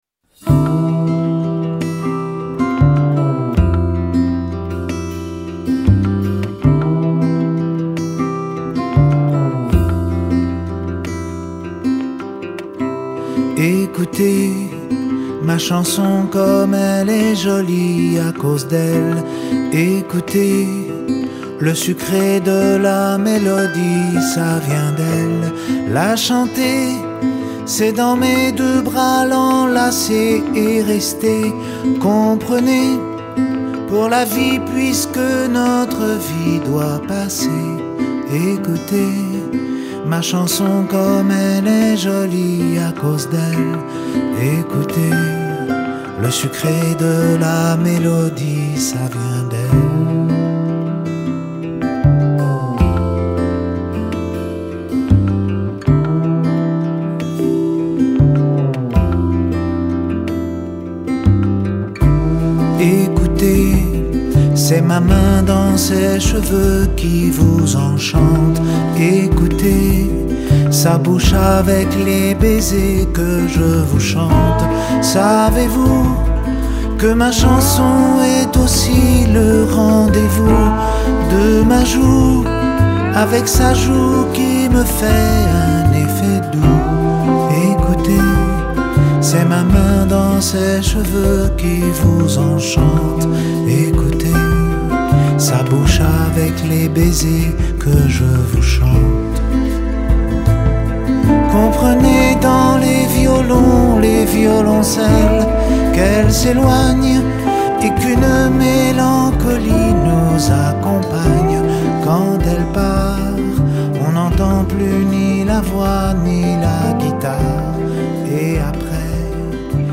tonalité DO majeur